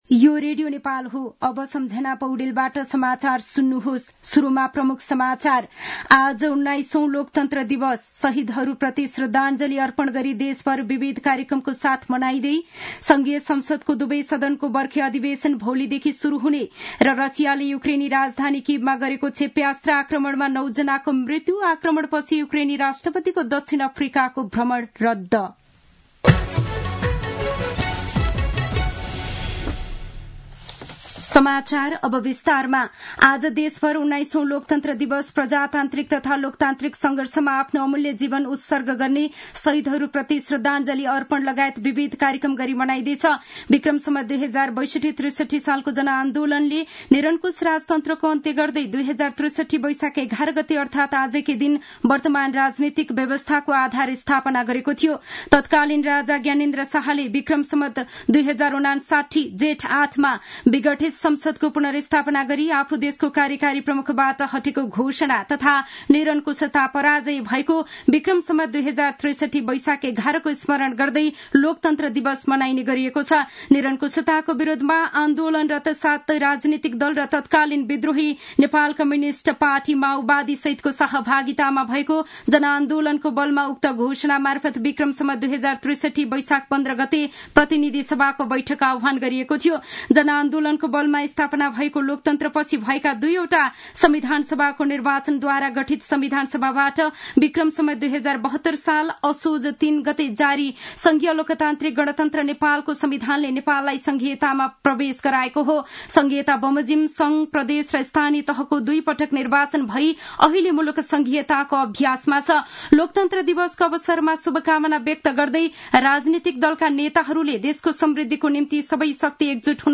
दिउँसो ३ बजेको नेपाली समाचार : ११ वैशाख , २०८२
3-pm-news-1-9.mp3